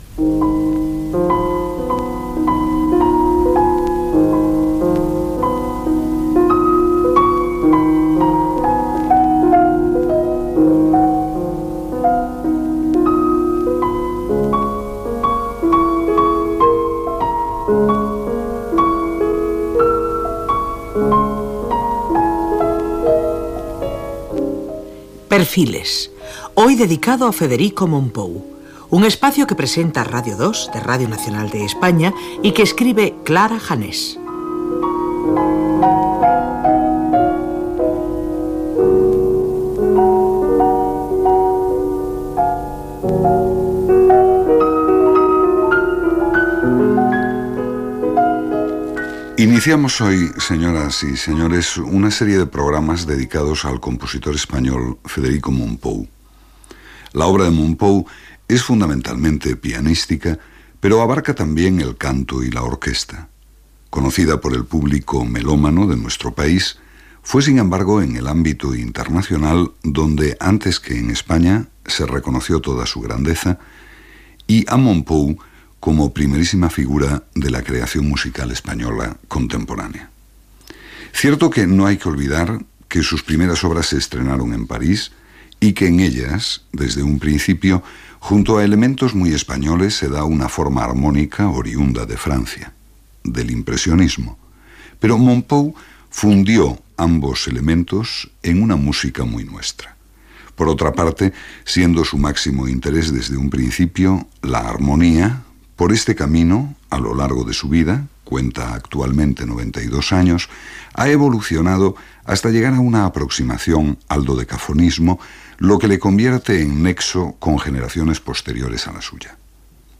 "Vida y obra de Federico Mompou". Careta, presentació de la sèrie i de la figura del compositor Frederic Mompou i tema musical
Musical